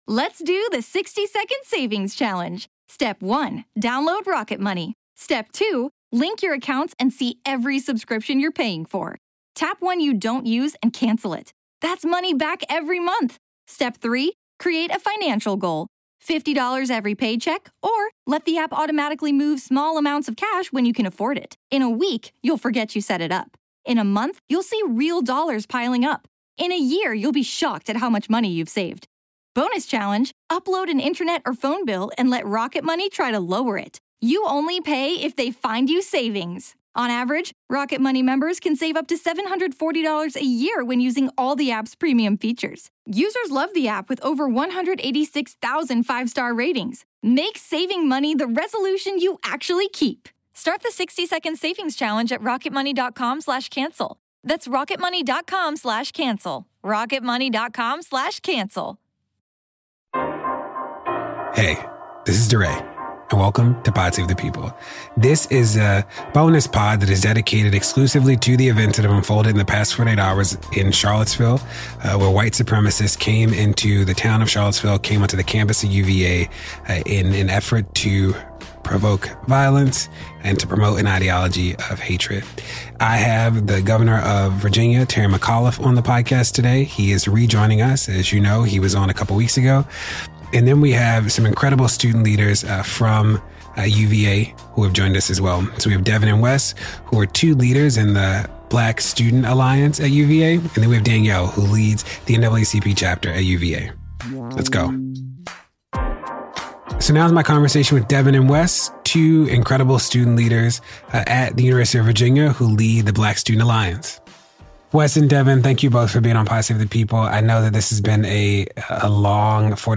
DeRay talks with Virginia Governor Terry McAuliffe about the deadly white supremacy violence in Charlottesville, VA’s police and state response, and the leadership needed to move forward locally and nationally.